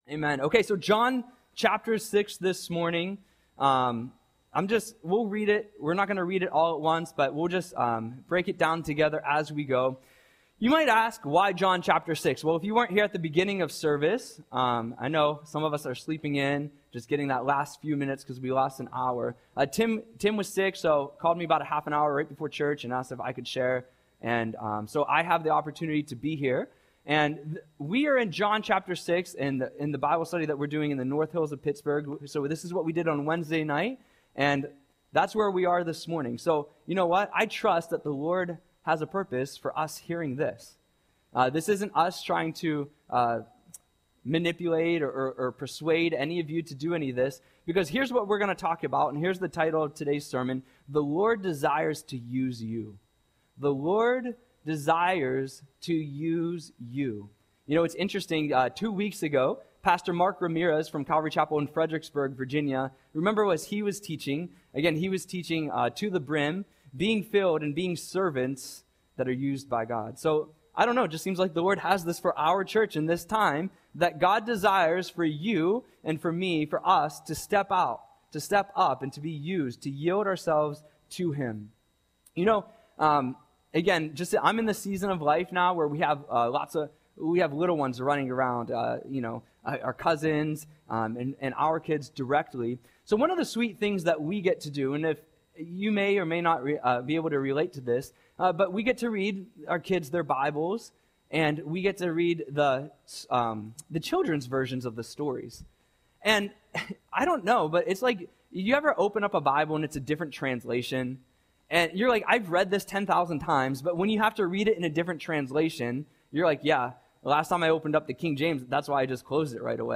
Audio Sermon - March 9, 2025